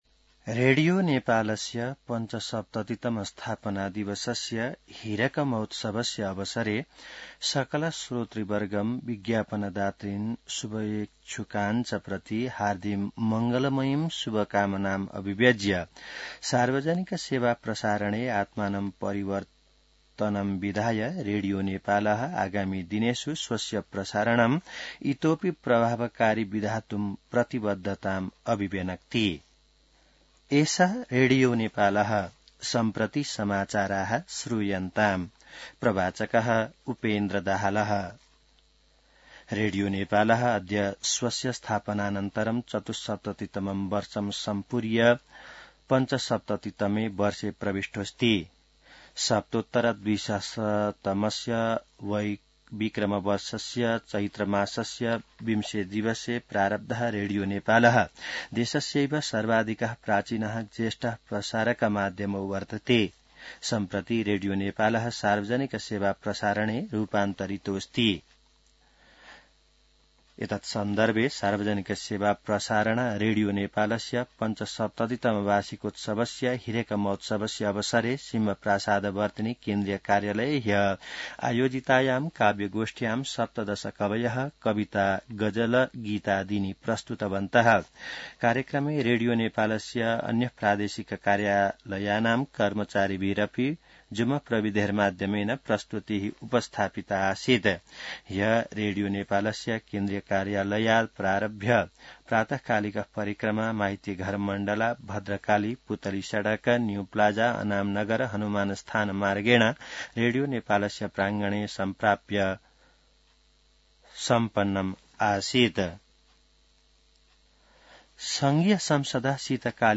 संस्कृत समाचार : २० चैत , २०८१